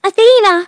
synthetic-wakewords
ovos-tts-plugin-deepponies_Rarity_en.wav